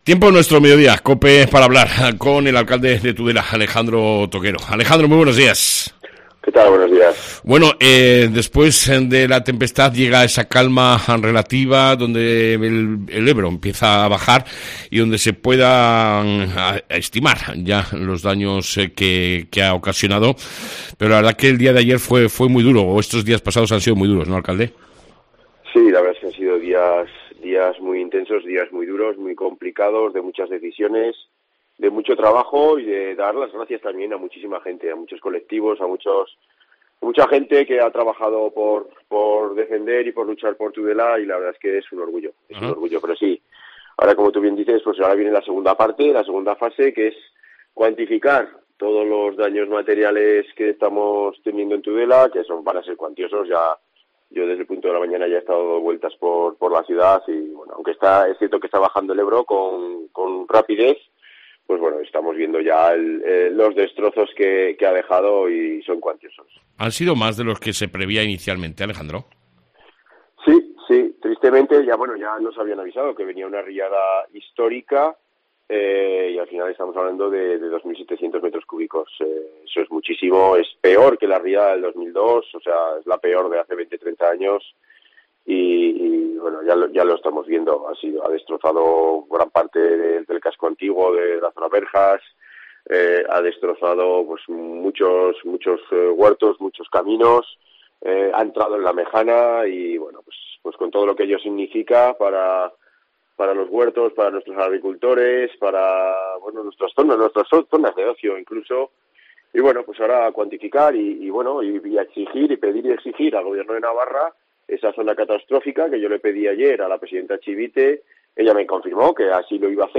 AUDIO: Hablamos con el alcalde de Tudela Alejandro Toquero tras las inundaciones acontecidas.